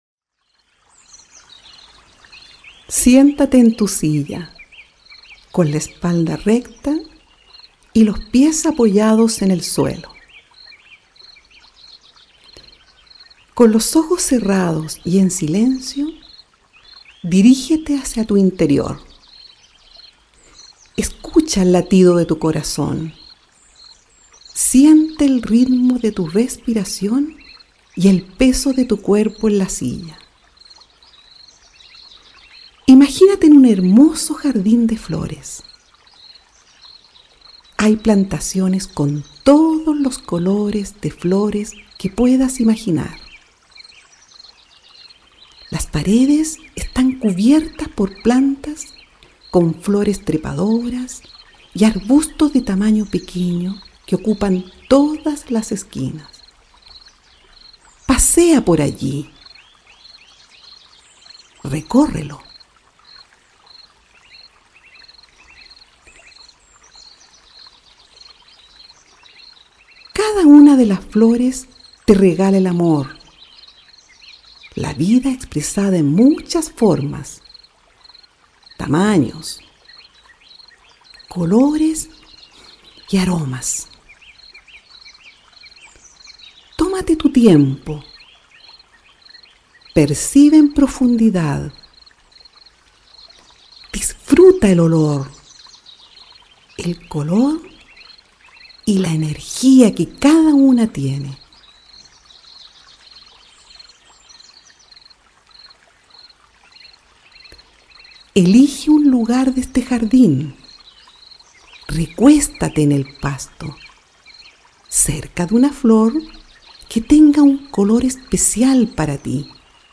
Ejercicio de relajación